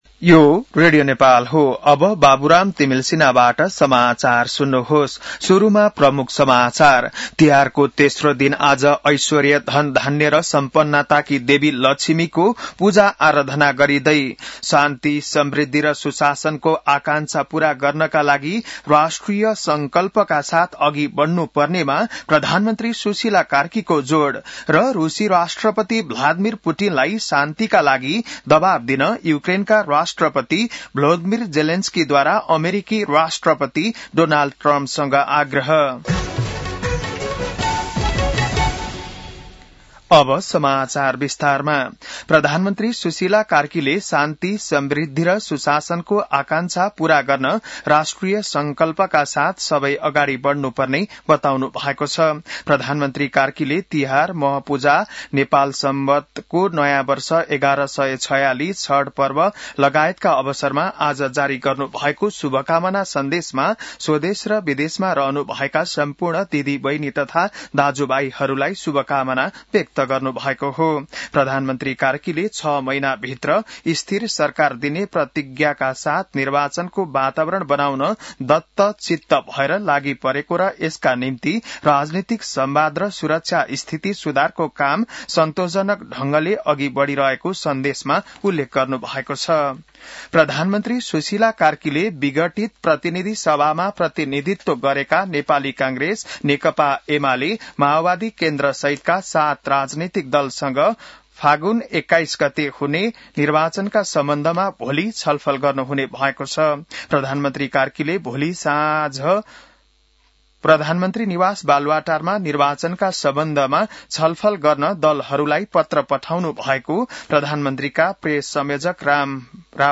बिहान ९ बजेको नेपाली समाचार : ३ कार्तिक , २०८२